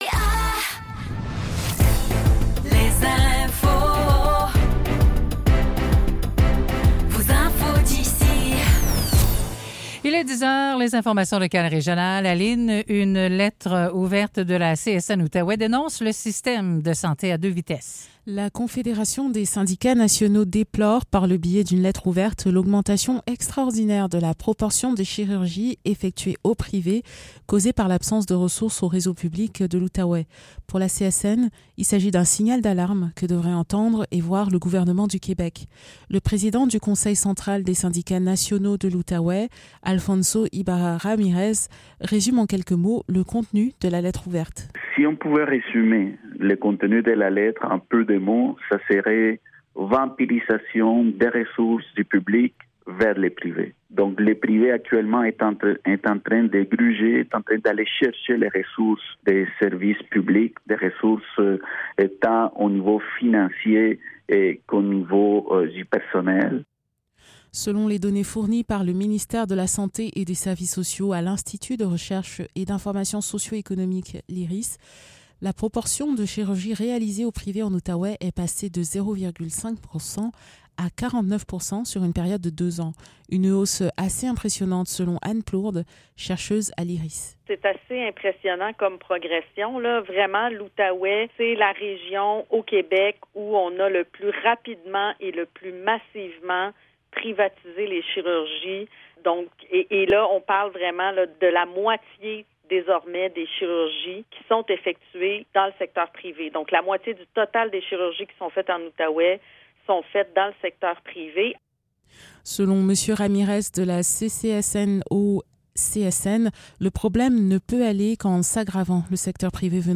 Nouvelles locales - 10 mai 2024 - 10 h